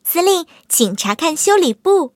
M22蝉修理完成提醒语音.OGG